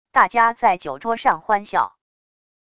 大家在酒桌上欢笑wav音频